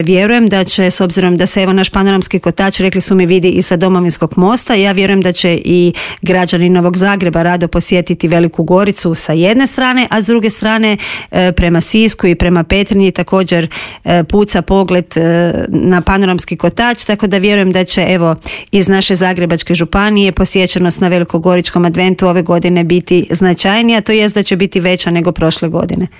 Advent u Gorici najavila je u Intervjuu Media servisa